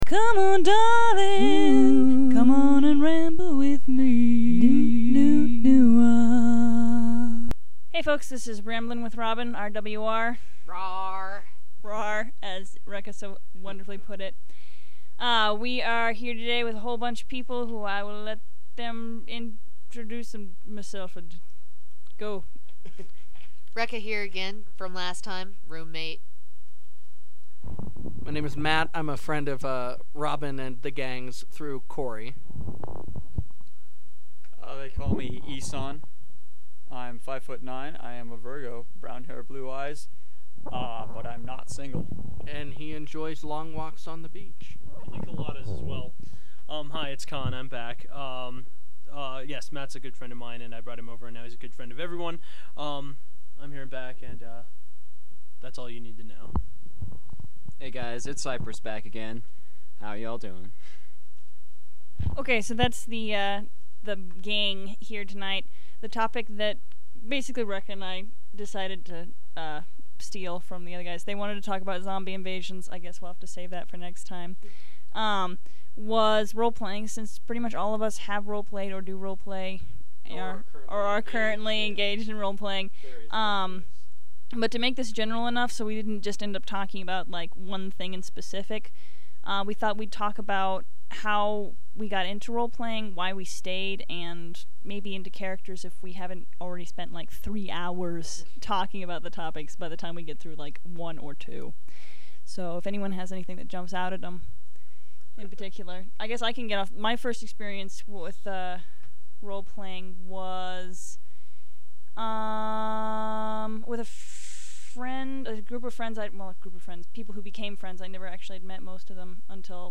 I hook up a microphone, sometimes alone, sometimes with special guests